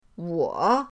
wo3.mp3